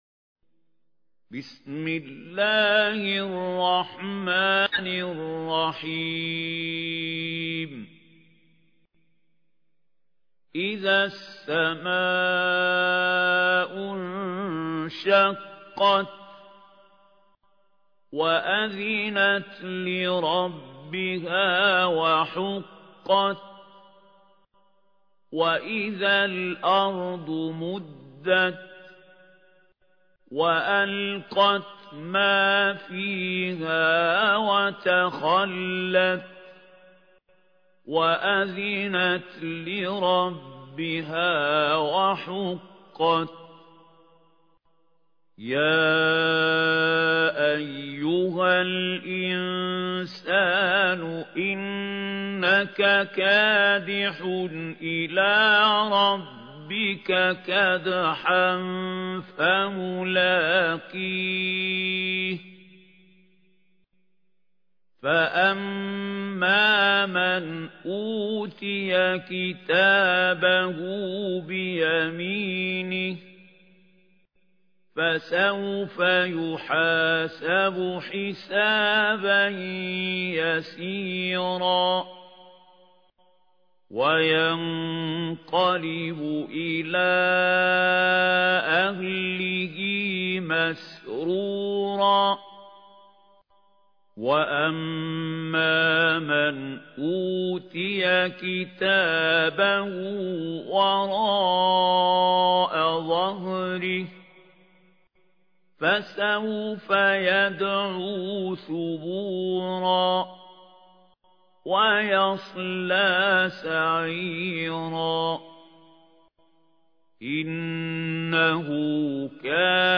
ترتيل
سورة الإنشقاق الخطیب: المقريء محمود خليل الحصري المدة الزمنية: 00:00:00